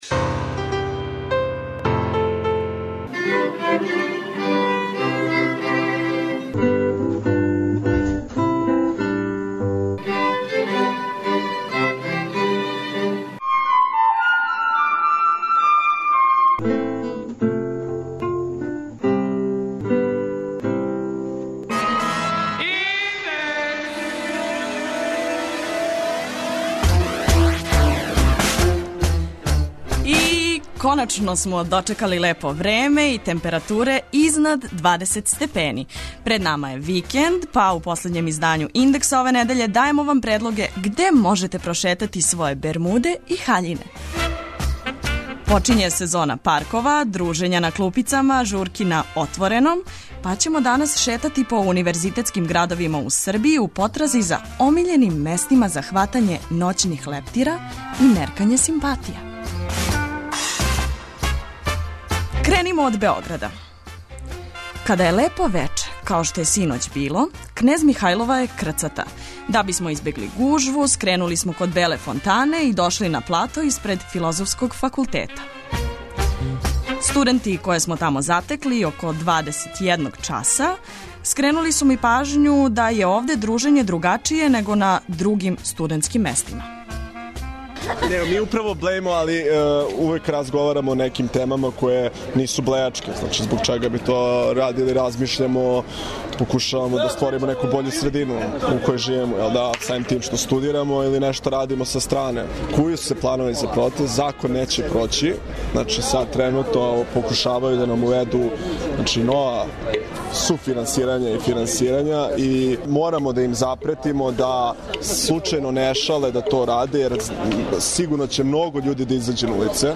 Питали смо студенте Универзитета у Србији која су њихова омиљена места за хватање ноћних лептира и меркање симпатија.
преузми : 19.19 MB Индекс Autor: Београд 202 ''Индекс'' је динамична студентска емисија коју реализују најмлађи новинари Двестадвојке.